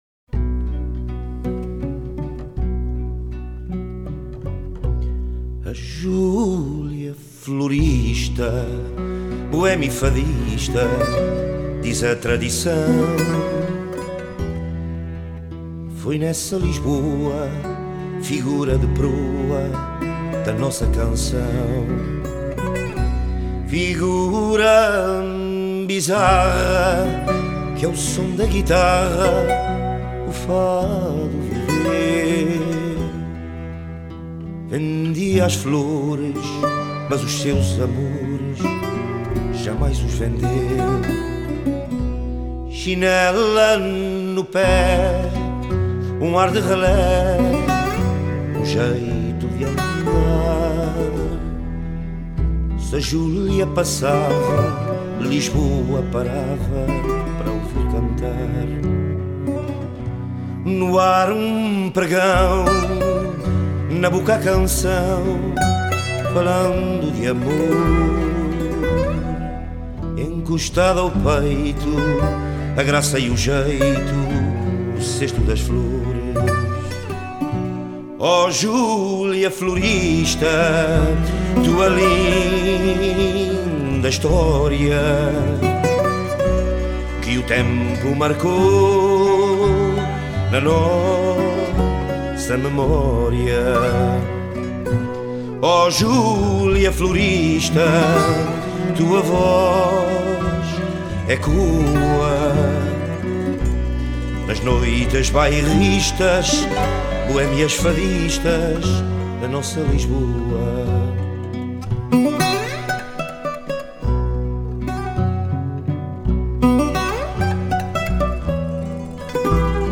Op feesten mocht live muziek die ons bijzonder aansprak
Fado-groep